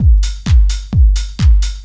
NRG 4 On The Floor 036.wav